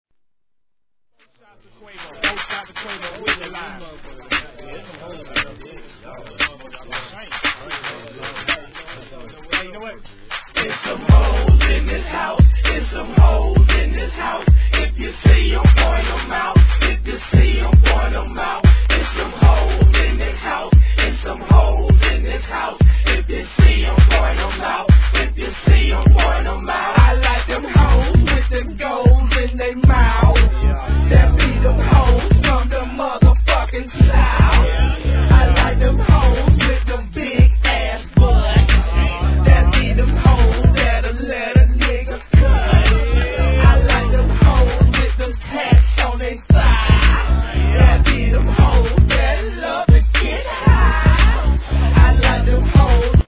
G-RAP/WEST COAST/SOUTH
「ビヤ〜チ！ビヤ〜チ!」連呼の強烈な作品!!